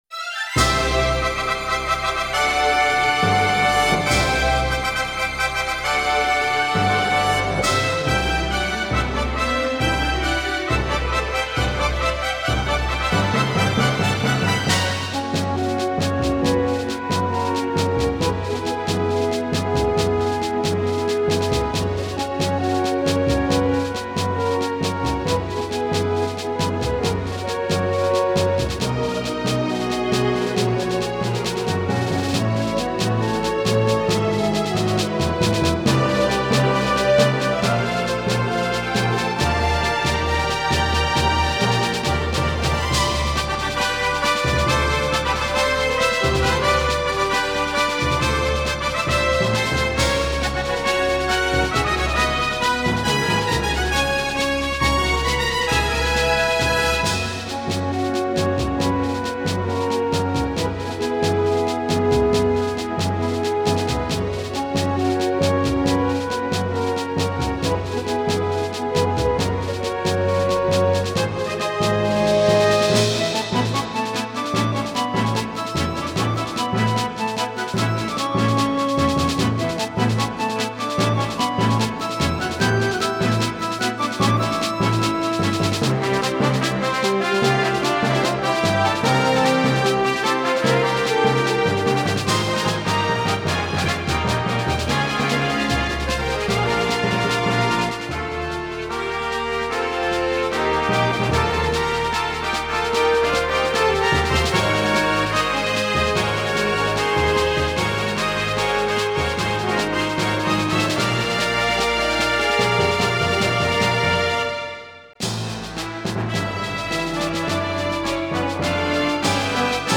エレクトーンでゲーム音楽を弾きちらすコーナー。